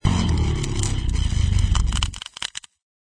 freeze.ogg